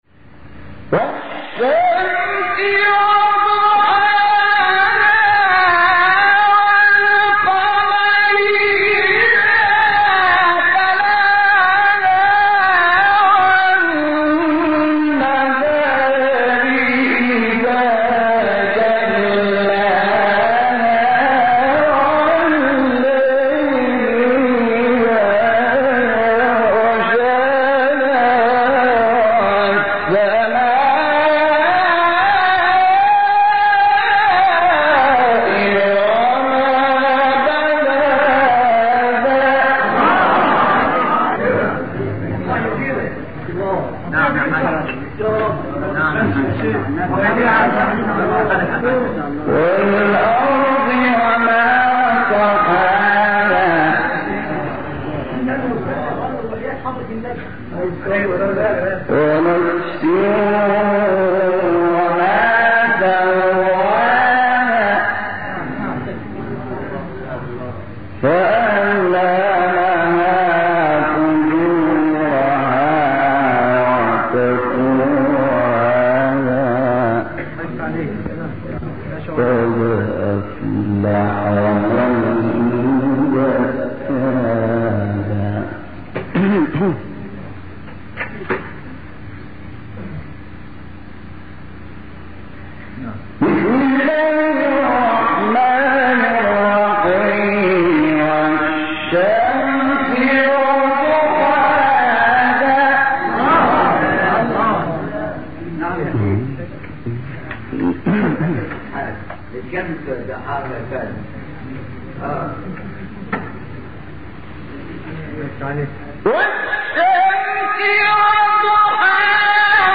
تلاوت زیبای سوره شمس استاد شحات | نغمات قرآن
سوره : شمس آیه: تمام سوره استاد : شحات محمد انور مقام : رست قبلی بعدی